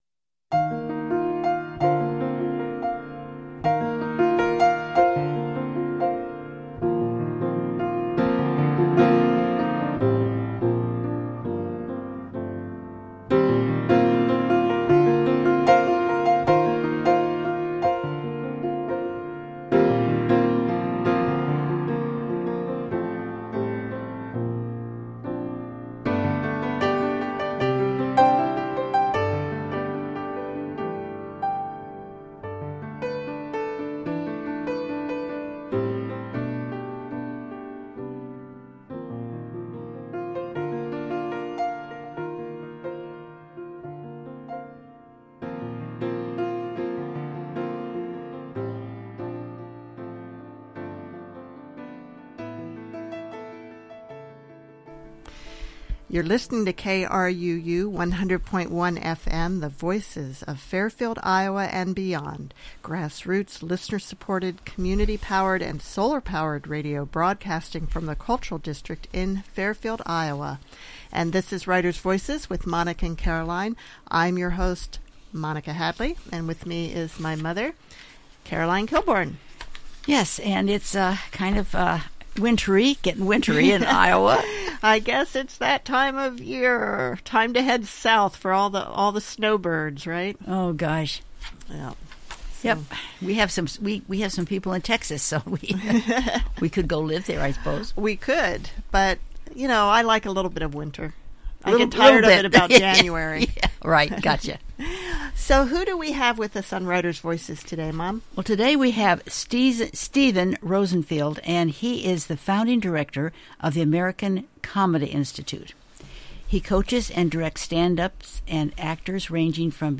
Get the inside scoop on comedy in this very entertaining conversation.